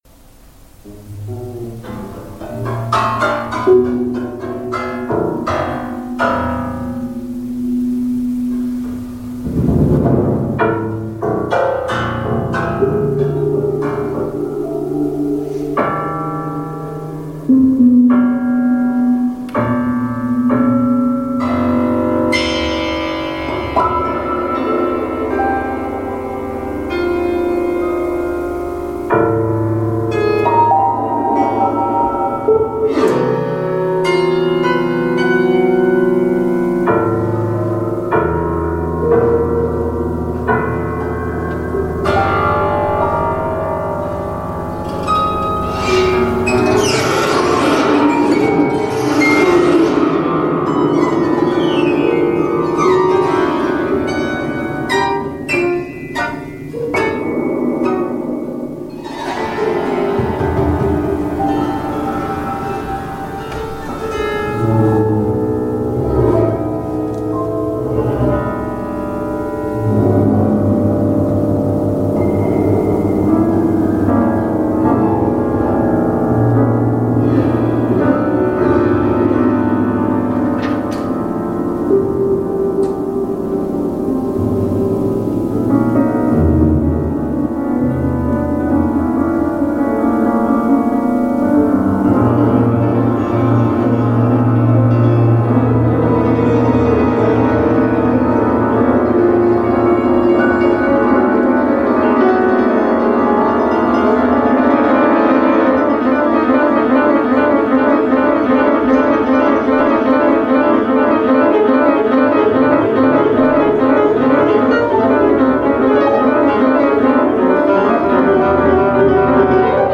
(Echtzeithalle, Herbstausgabe 2019)
Laptop-Gitarre
Klavier